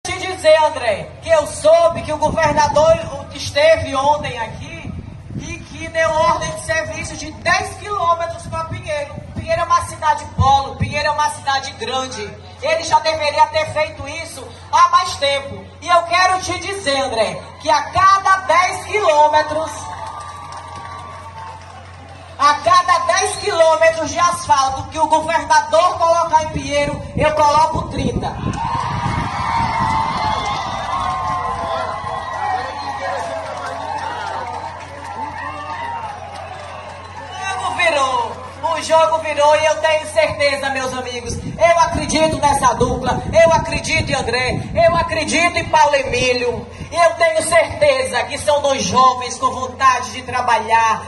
Ouça o áudio da senadora desafiando o governador: